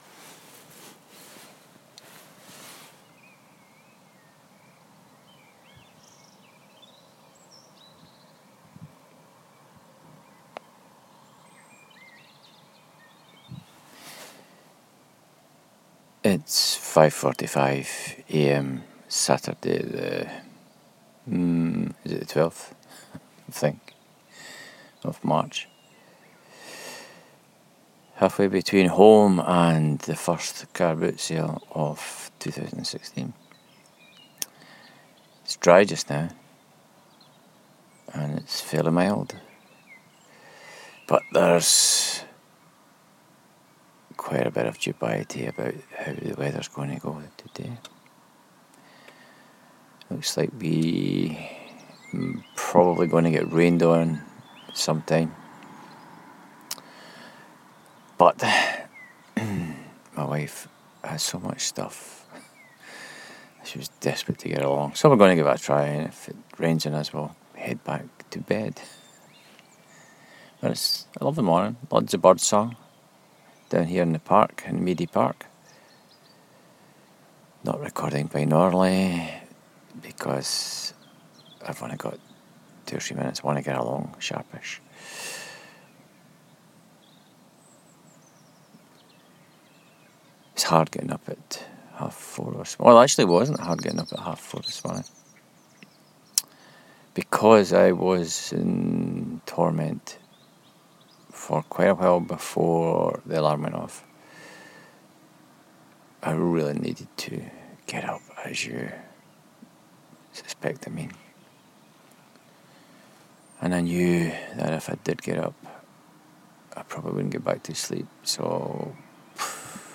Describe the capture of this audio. Share Facebook X Next Morning thoughts from first car boot of the year.